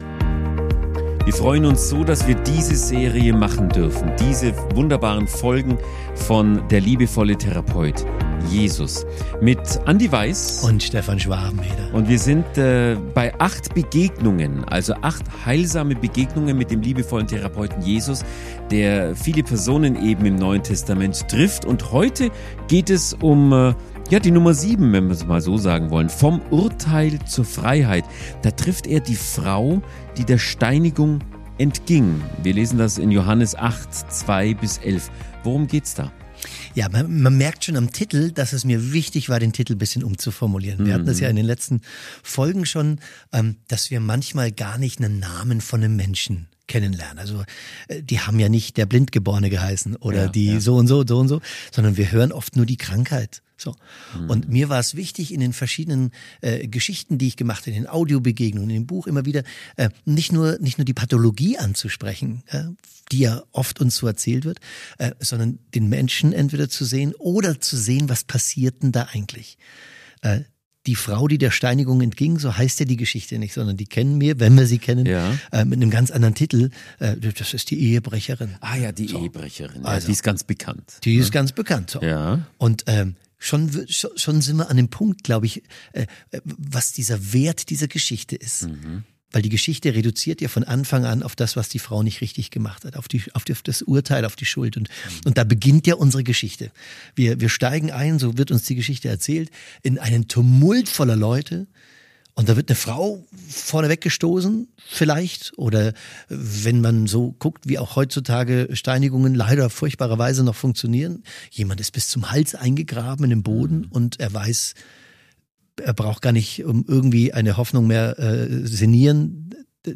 Ein Gespräch über Schuld, Vergebung, innere Freiheit – und die Kraft eines einzigen Satzes.
Aber ehrliche, leise, starke Worte.